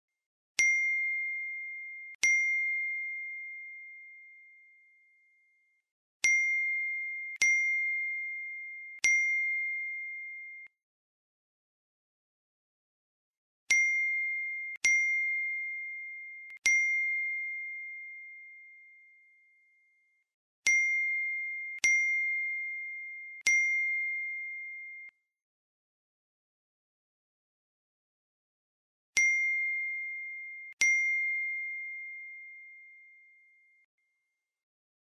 Tiếng chuyển khoản ngân hàng liên tục Ting Ting….
Thể loại: Hiệu ứng âm thanh
Description: Tải hiệu ứng âm thanh tiếng chuyển khoản ngân hàng liên tục Ting Ting..., âm thanh may mắn tiền về :) mp3 edit video, chỉnh sửa video.
tieng-chuyen-khoan-ngan-hang-lien-tuc-ting-ting-www_tiengdong_com.mp3